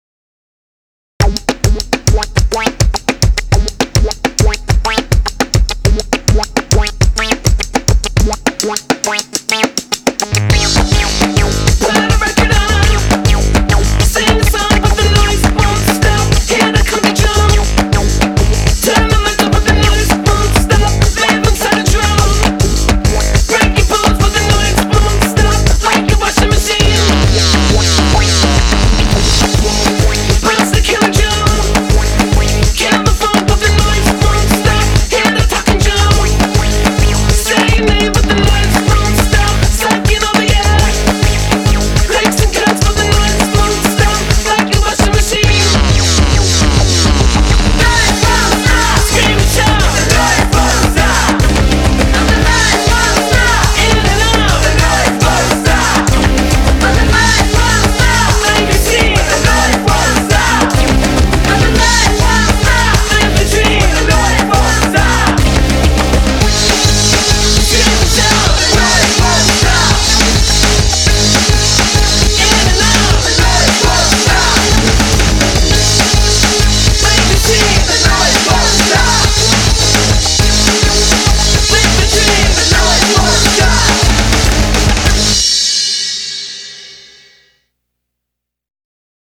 BPM206
Audio QualityMusic Cut